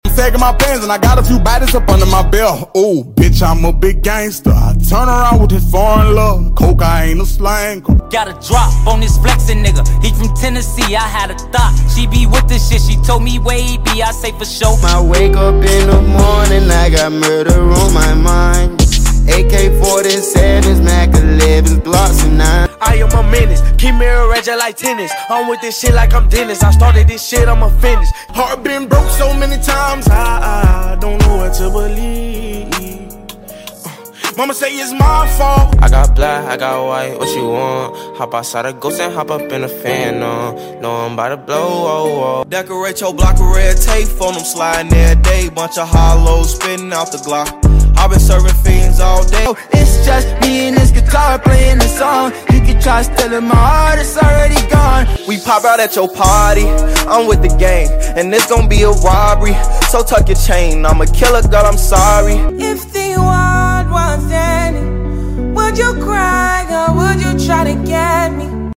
BEST GENIUS OPEN MIC PERFORMANCES sound effects free download